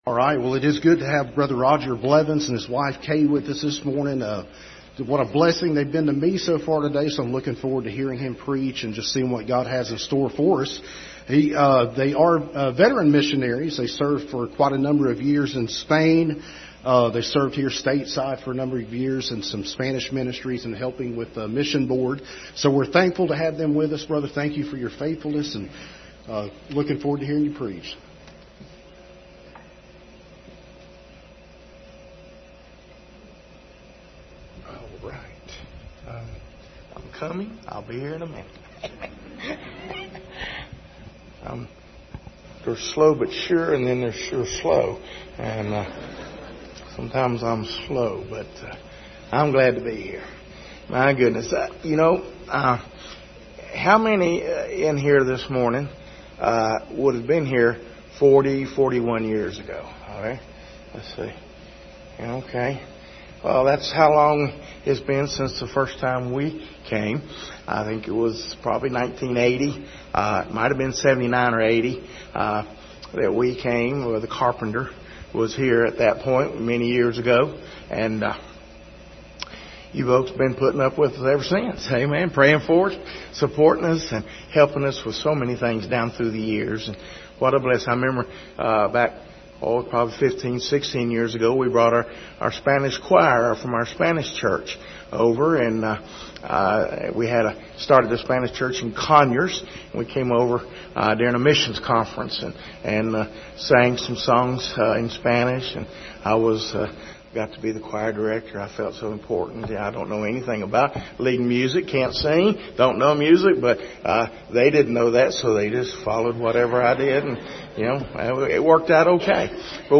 Passage: Mark 10:17-22 Service Type: Sunday Morning